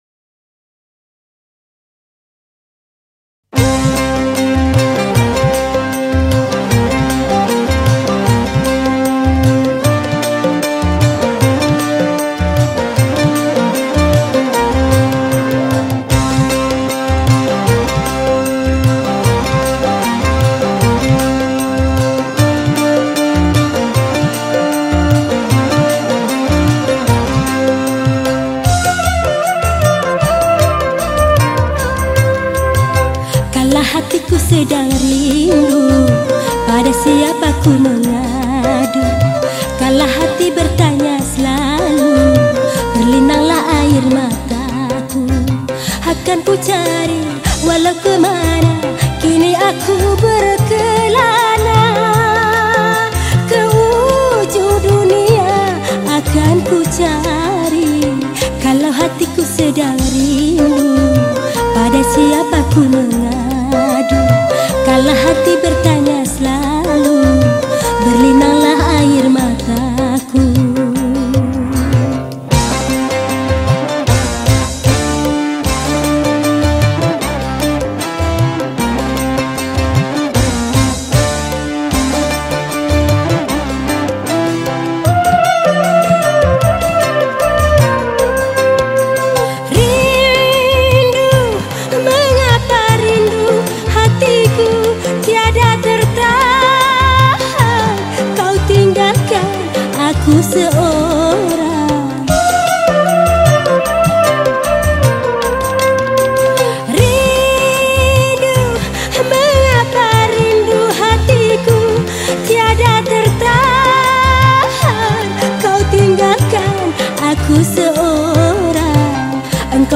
Skor Angklung